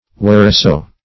whereso - definition of whereso - synonyms, pronunciation, spelling from Free Dictionary Search Result for " whereso" : The Collaborative International Dictionary of English v.0.48: Whereso \Where"so\, adv.